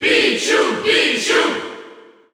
Category: Pichu (SSBU) Category: Crowd cheers (SSBU) You cannot overwrite this file.
Pichu_Cheer_French_SSBU.ogg